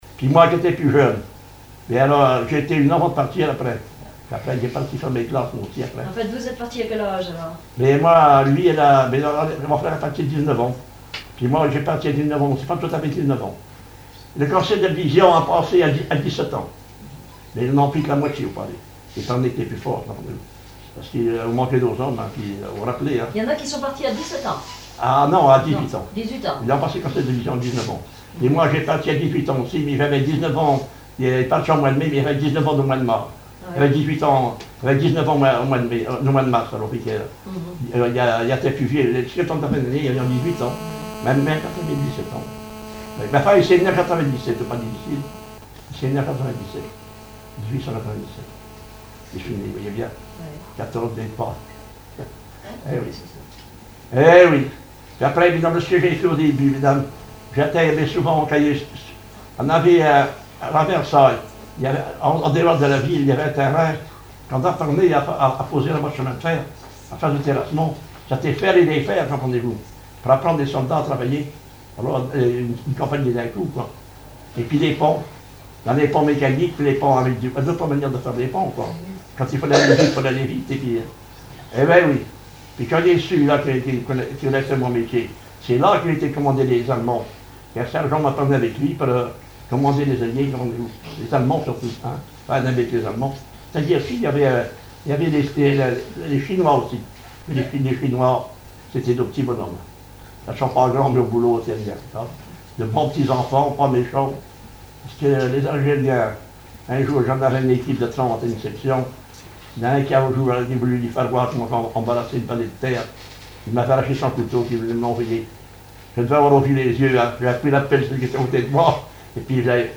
témoignage sur le vécu durant la guerre 1914-1918
Catégorie Témoignage